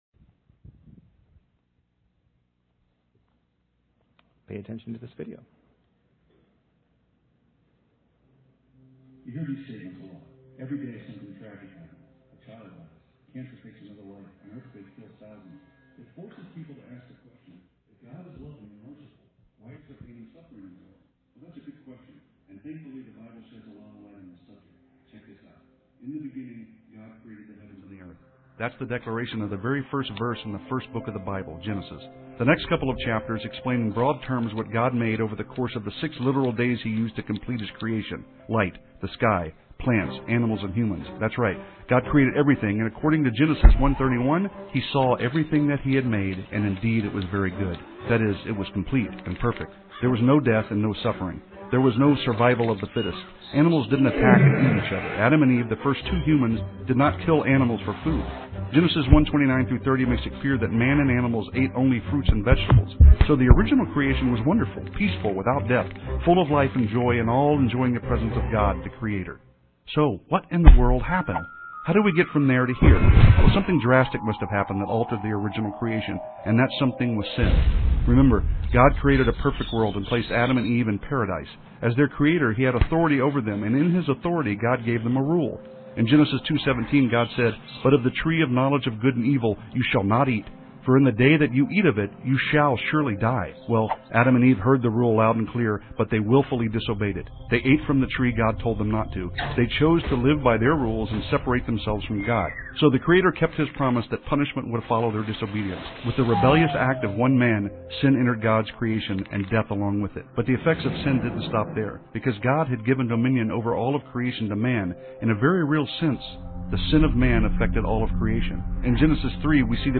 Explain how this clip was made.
Main Service am (main lesson starts at 9:00 minute mark & ends at 57:00 mark with video in between)